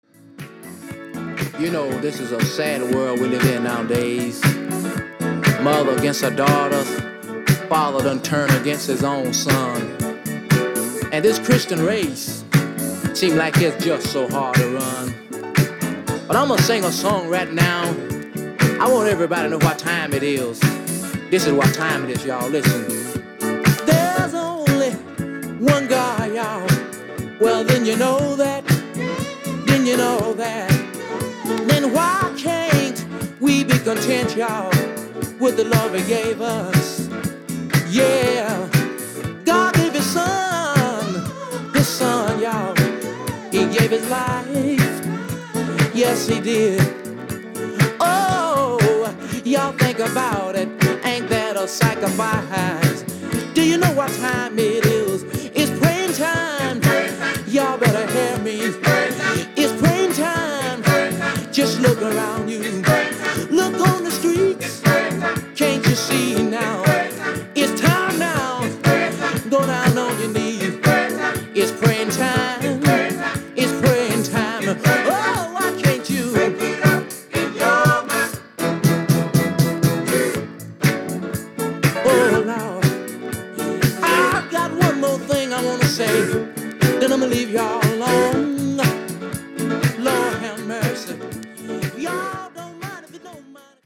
Funk.Discoな感じもあって楽しめると思います。